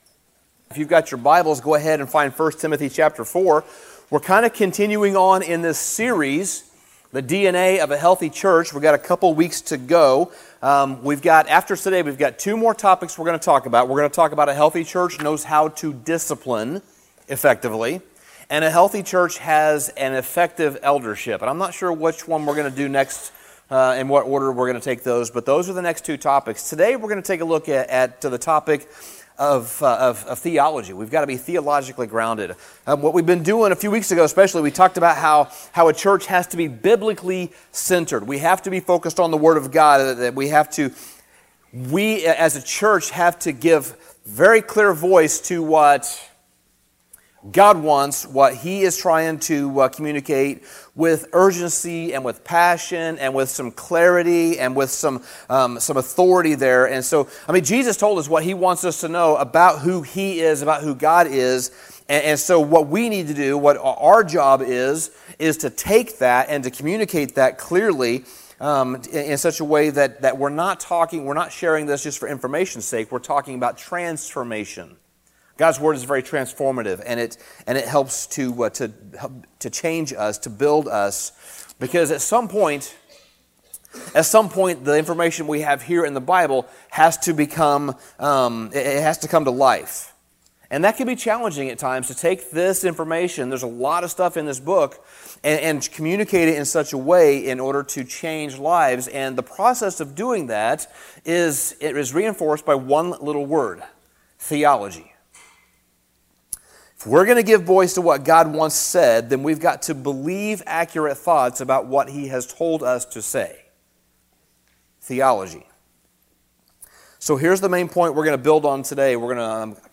Sermon Summary Theology often comes with musty, boring perceptions.